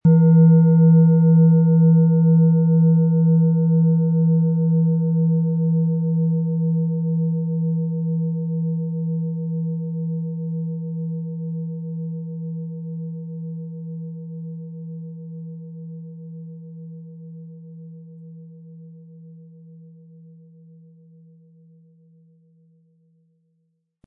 • Mittlerer Ton: Mond
• Höchster Ton: Mond
Im Sound-Player - Jetzt reinhören können Sie den Original-Ton genau dieser Schale anhören.
PlanetentöneHopi Herzton & Mond
MaterialBronze